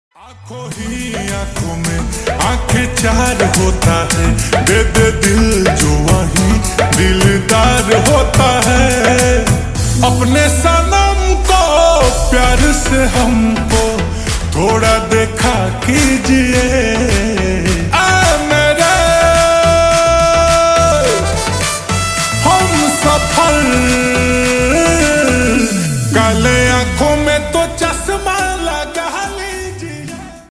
Bhojpuri Song
Indulge in the infectious beats and captivating rhythm of